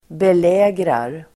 Uttal: [bel'ä:grar]